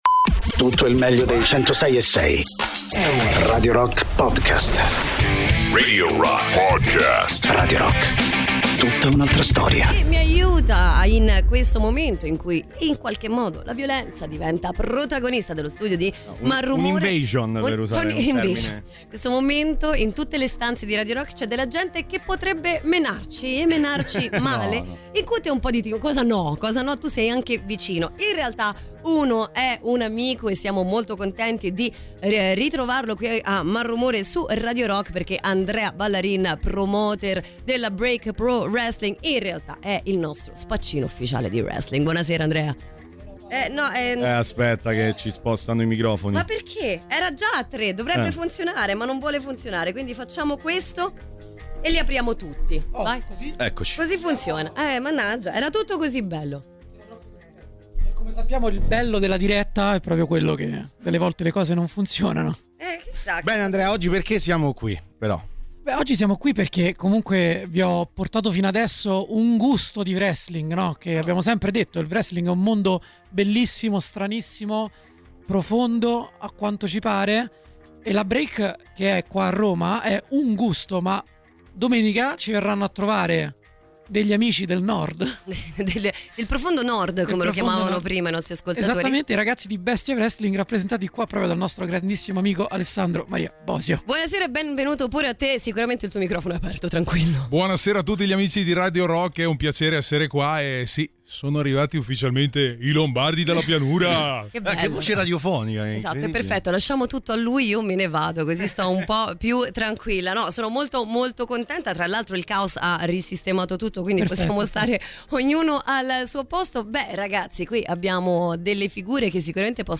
Intervista: Santino Marella (07-11-25)